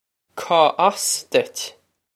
Pronunciation for how to say
Caw oss ditch?
This is an approximate phonetic pronunciation of the phrase.